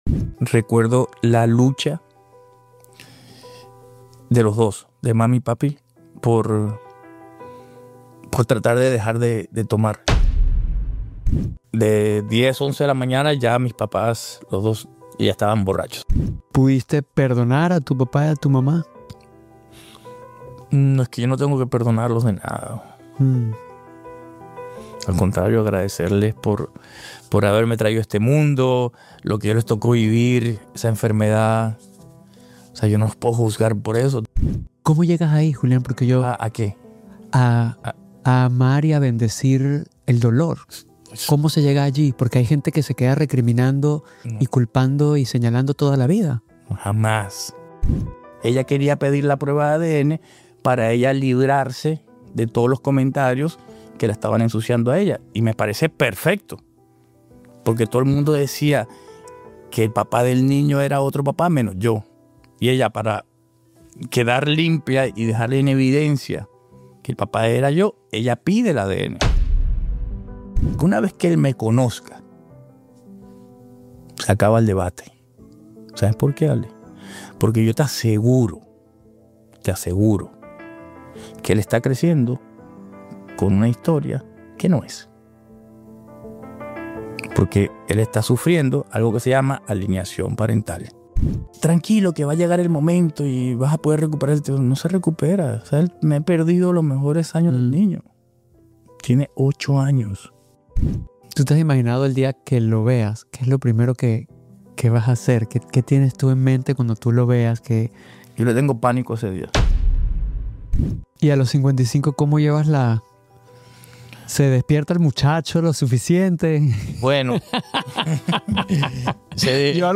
| CHABÁN Podcast Episode 78 · Jul 14, 2025, 02:57 PM Headliner Embed Embed code See more options Share Facebook X Subscribe Cuando invité a Julián Gil al Chabán Podcast, sabía que venía un episodio poderoso… pero no imaginé cuánto.